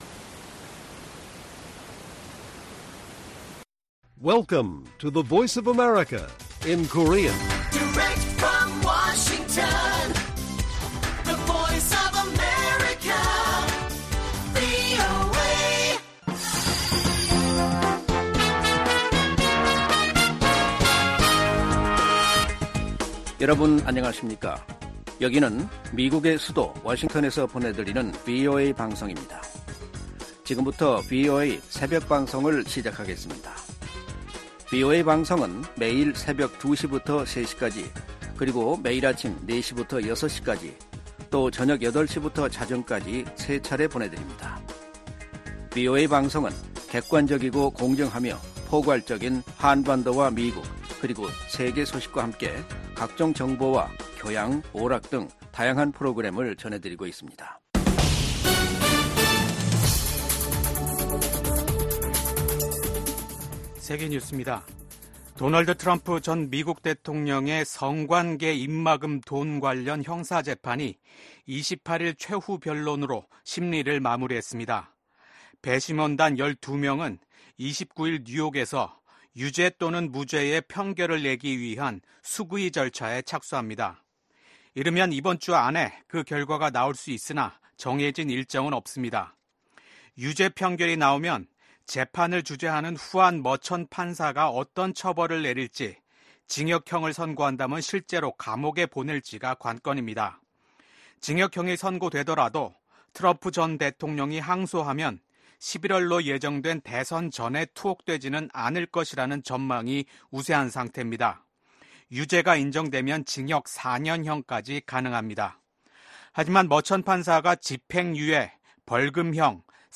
VOA 한국어 '출발 뉴스 쇼', 2024년 5월 30일 방송입니다. 미 국무부는 최근의 한일중 정상회담과 관련해 북한 문제에 대한 중국의 역할이 중요하다는 점을 거듭 강조했습니다. 전 세계 주요국과 국제기구들이 계속되는 북한의 미사일 발사는 관련 안보리 결의에 대한 명백한 위반이라고 비판했습니다.